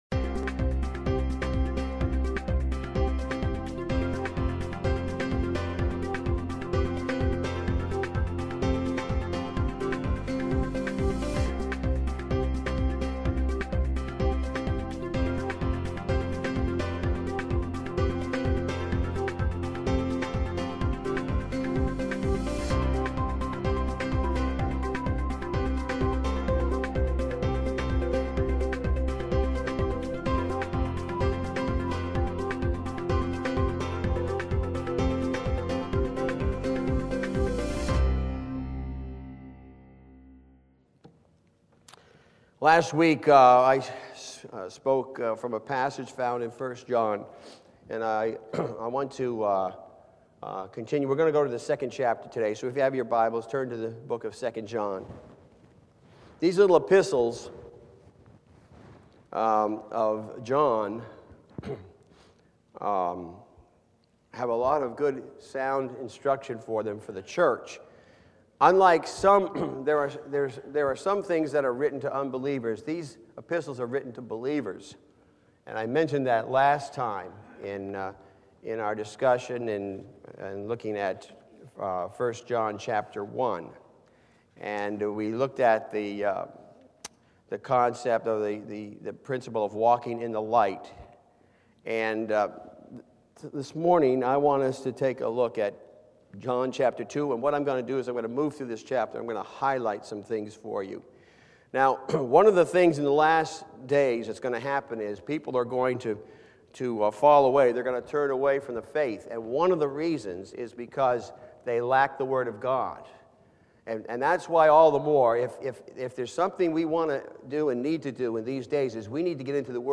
← Newer Sermon Older Sermon →